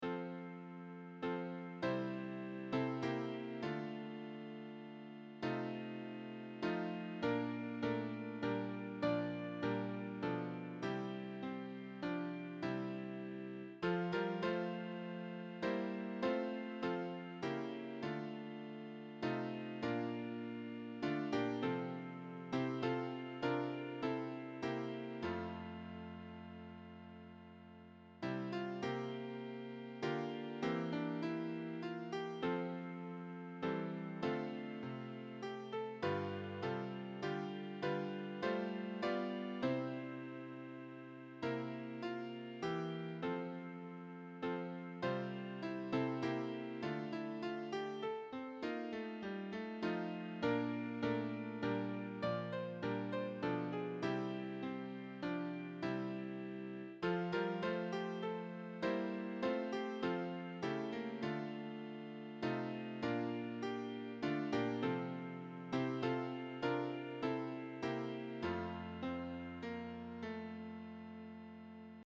Sorry about the MIDI-quality mp3 files.
piano prelude
Piano